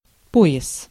Ääntäminen
France (Paris): IPA: [yn zon]